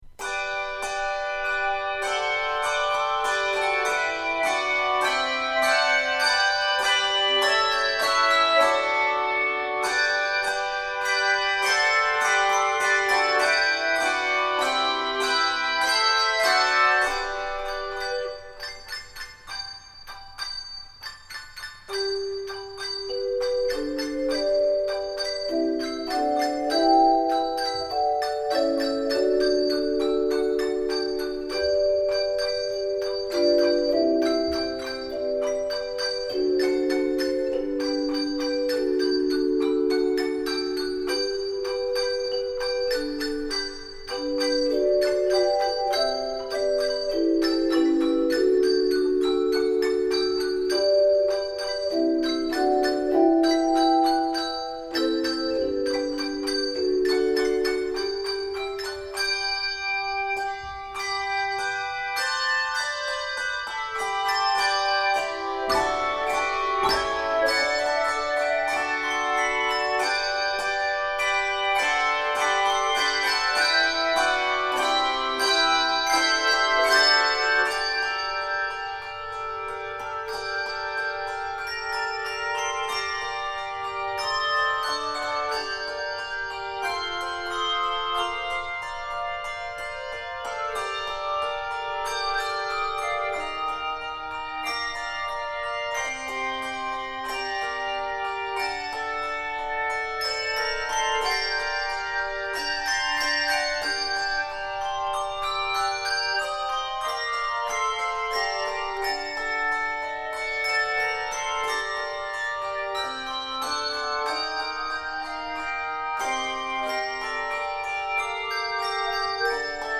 for 2–3 octave handbells and 2–3 octave handchimes.